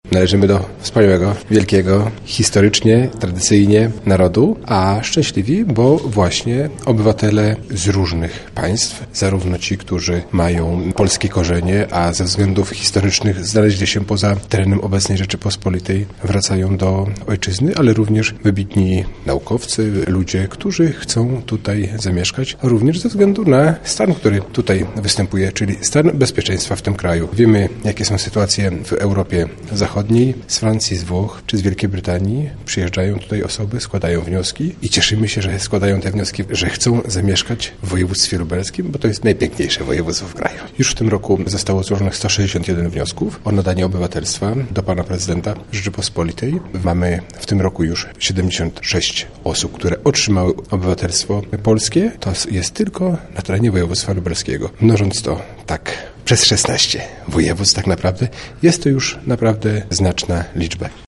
Wręczający akty obywatelstwa wicewojewoda lubelski Robert Gmitruczuk dodał, że takie uroczystości są bardzo wzruszające.